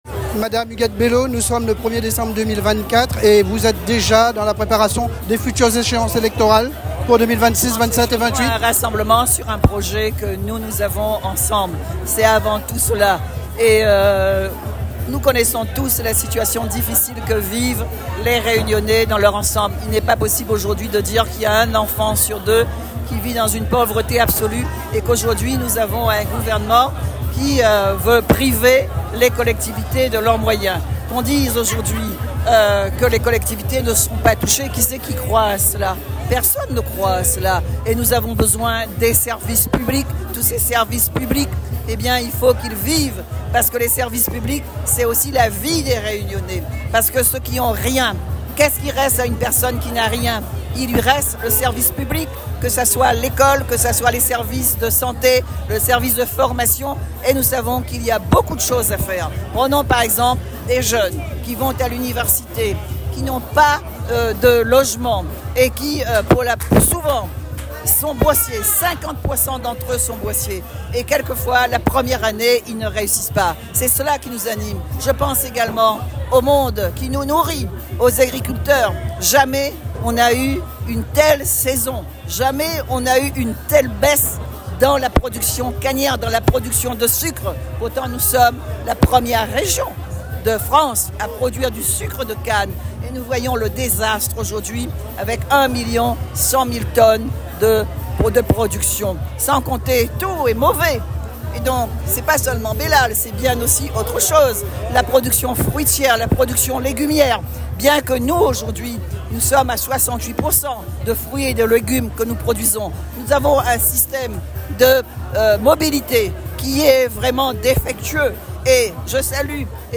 C’est dans cet esprit que le rassemblement des forces progressistes de La Réunion a réuni près de 2500 personnes ce dimanche 1er décembre, à Saint-Denis, à l’invitation de plusieurs partis et organisations de gauche et avec le soutien de nouvelles forces : “Génération Ecologie” et “La Réunion Plus Verte”.
Huguette-Bello-1er-decembre-2024.m4a